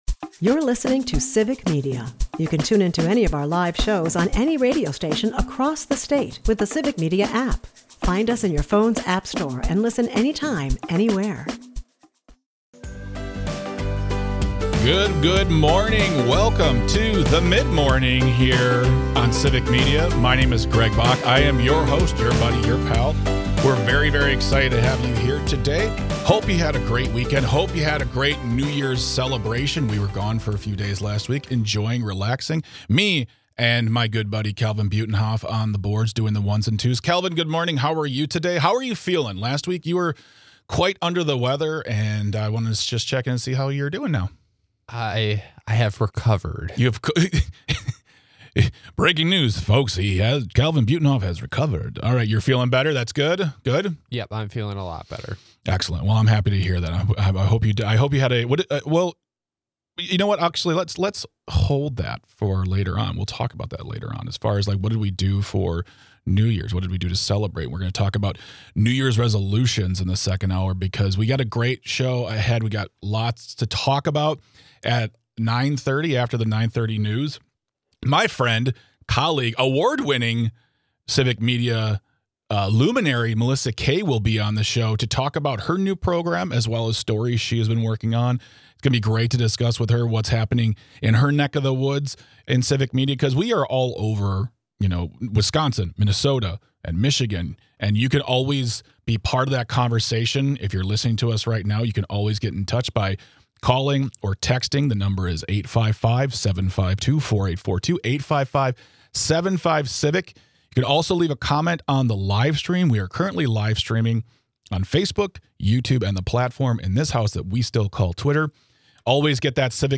Everything kicks off with an announcement from Minnesota Governor Tim Walz.
And from what we've been told, there is also a pigeon attending this conversation, as well. As always, thank you for listening, texting and calling, we couldn't do this without you!